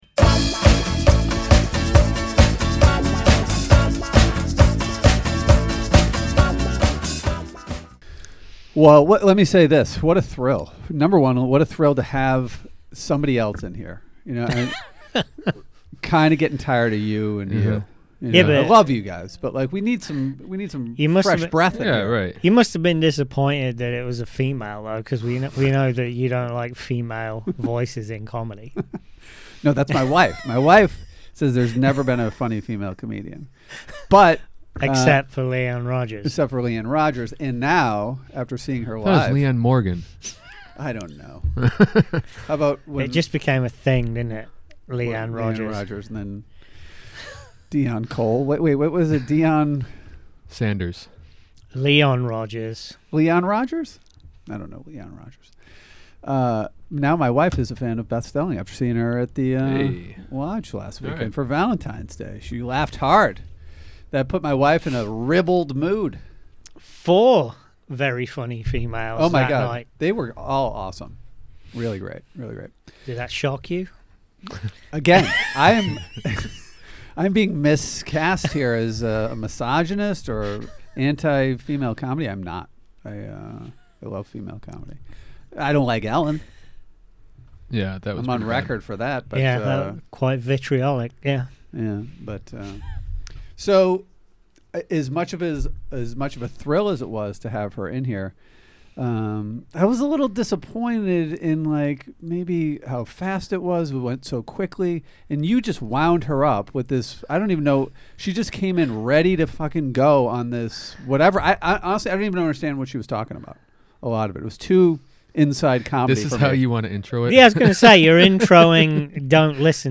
Special Episode: Beth Stelling Interview